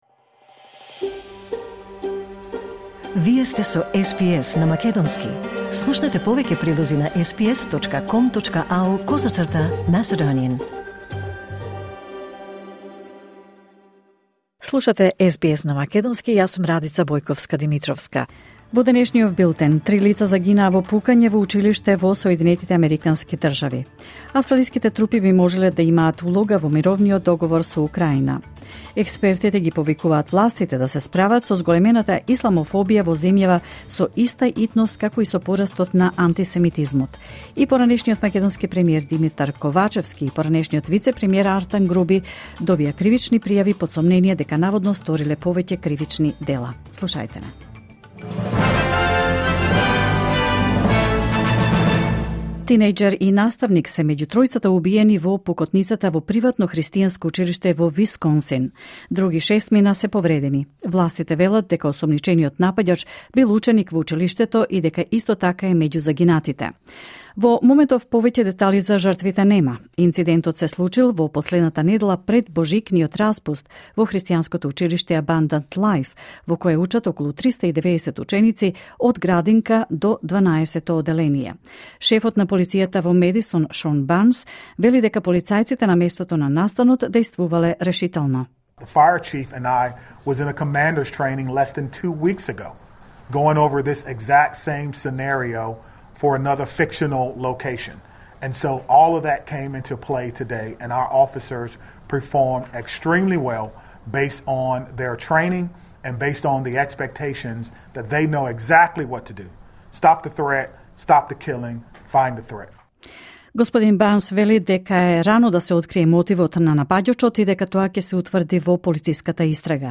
SBS News in Macedonian 17 December 2024